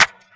Swervo Snap.wav